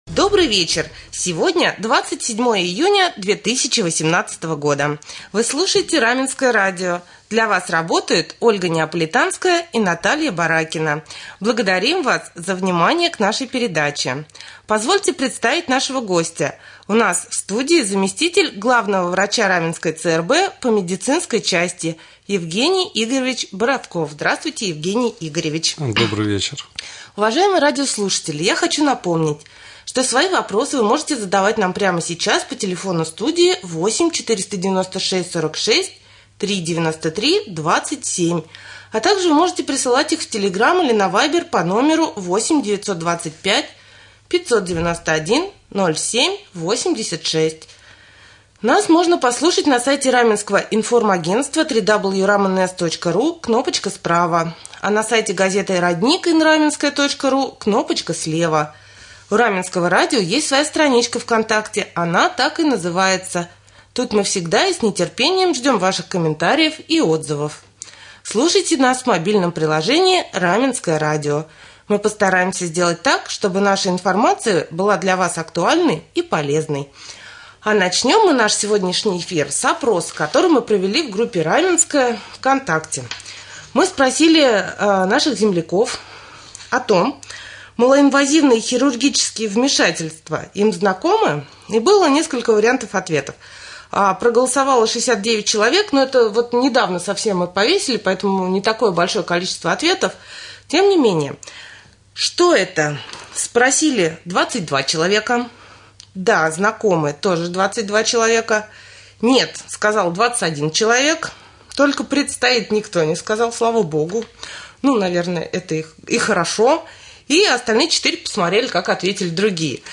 повтора прямого эфира